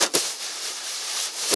56BRUSHSD3-L.wav